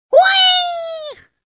из игры
Звук запуска птицы из рогатки